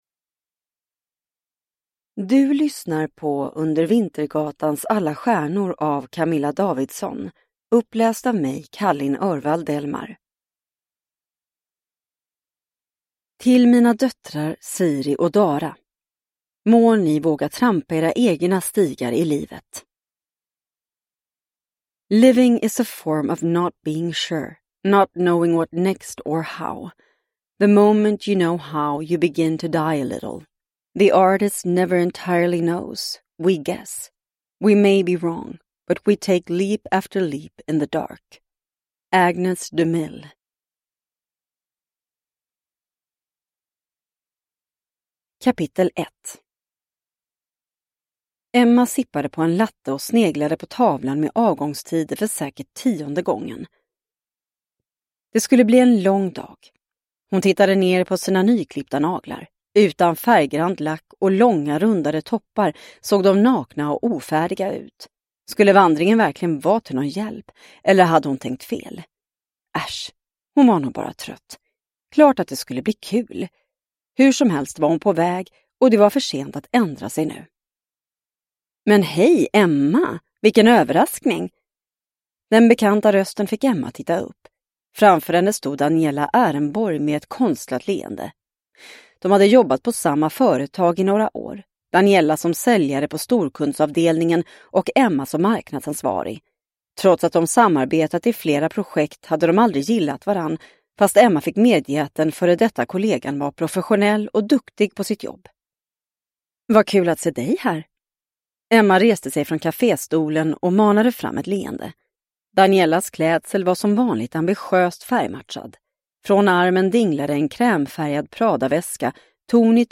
Under vintergatans alla stjärnor – Ljudbok – Laddas ner